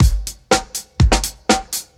• 121 Bpm Drum Loop A Key.wav
Free breakbeat - kick tuned to the A note. Loudest frequency: 2043Hz
121-bpm-drum-loop-a-key-h9i.wav